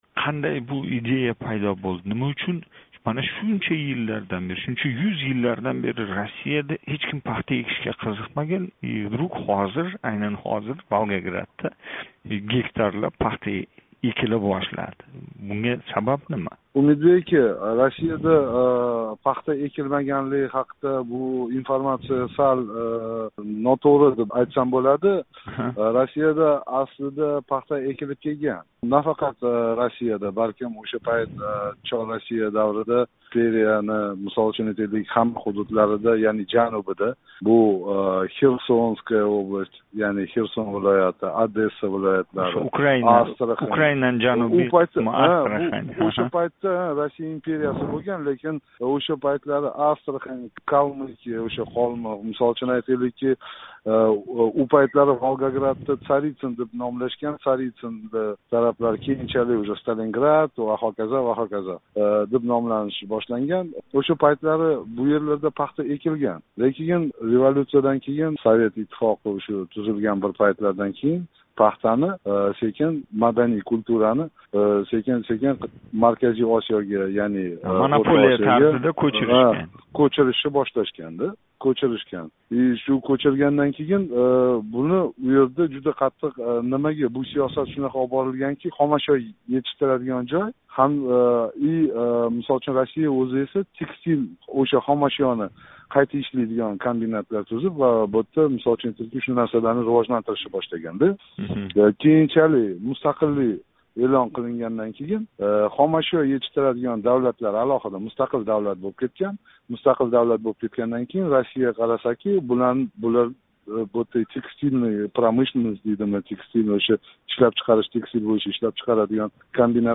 Профессор
суҳбат.